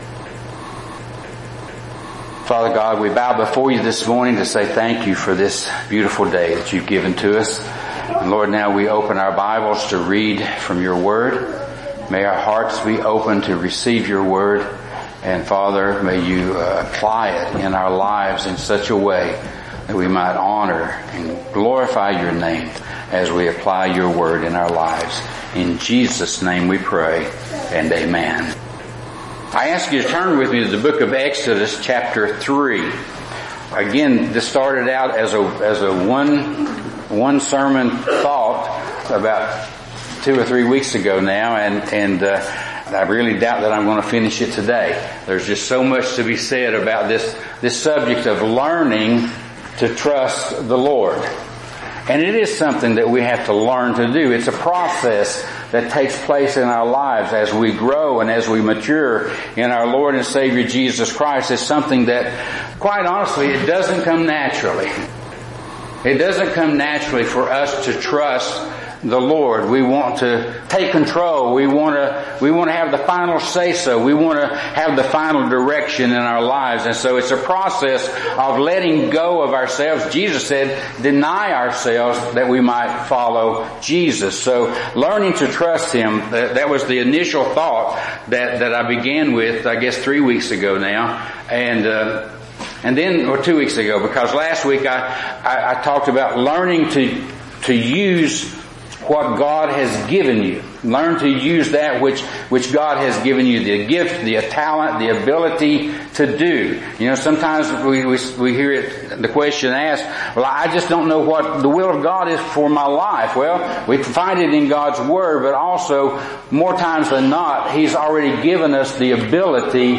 Know The One You Trust Aug 17 In: Sermon by Speaker